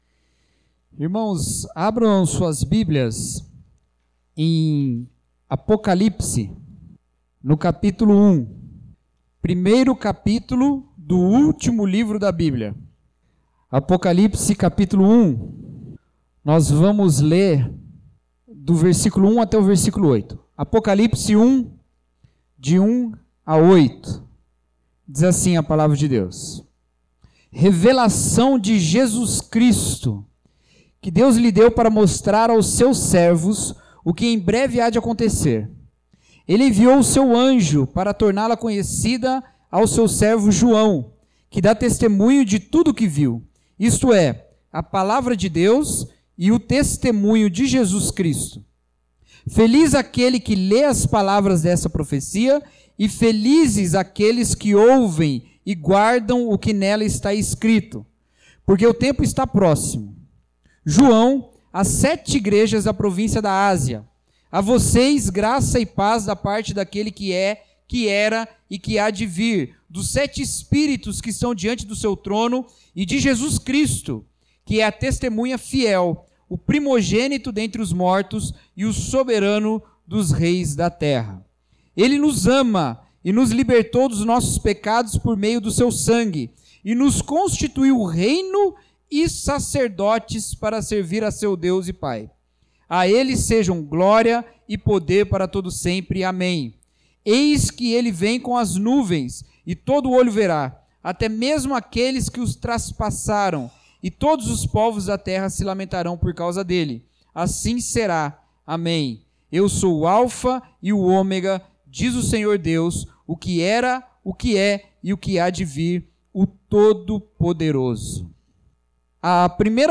Mensagem: Quem tem medo do Apocalipse?